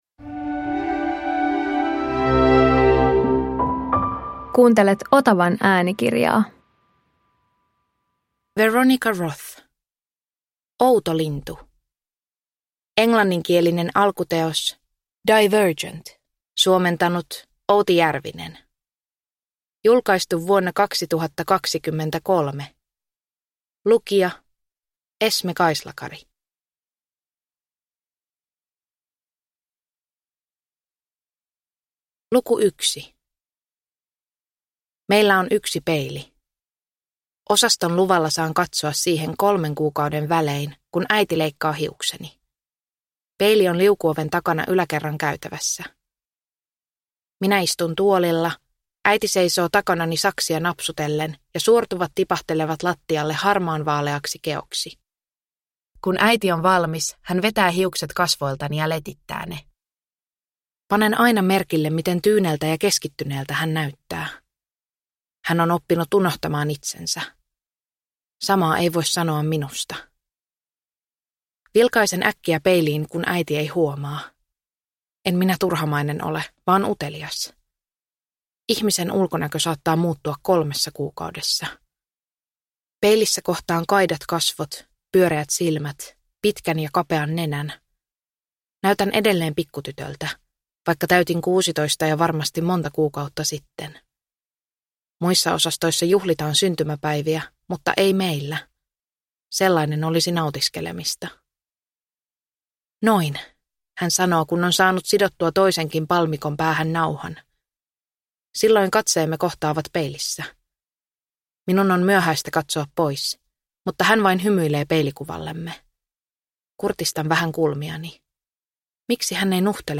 Outolintu – Ljudbok